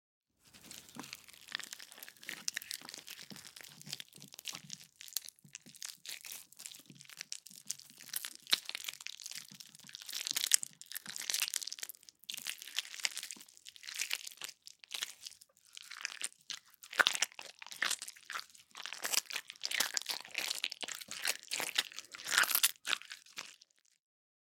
Звук размягчаем банан ладонью